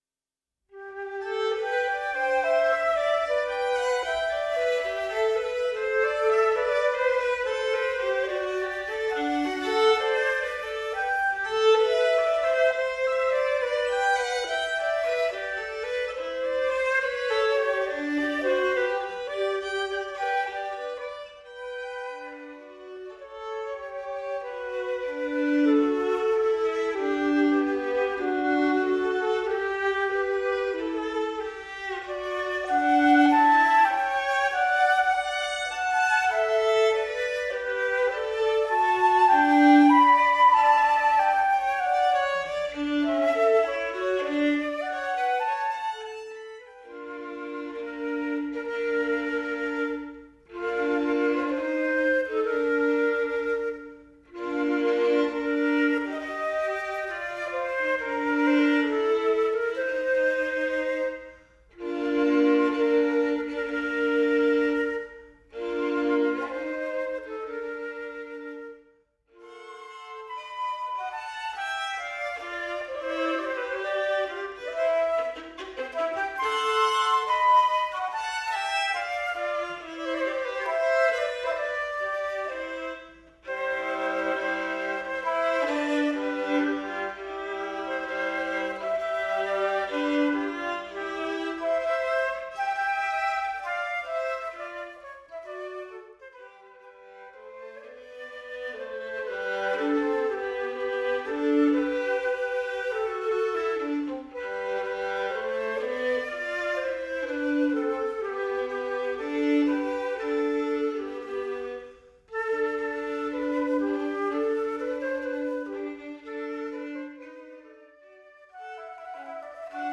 Violin – Flute
duet_violin-flute.mp3